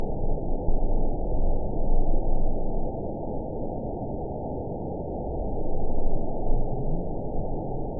event 920540 date 03/28/24 time 21:09:52 GMT (1 year, 1 month ago) score 9.28 location TSS-AB07 detected by nrw target species NRW annotations +NRW Spectrogram: Frequency (kHz) vs. Time (s) audio not available .wav